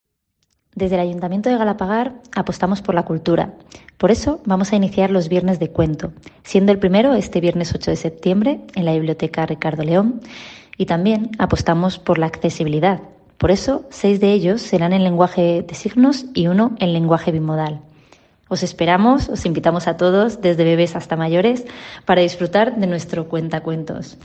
La concejal de Cultura de Galapagar, Paloma Lorenzo, explica la iniciativa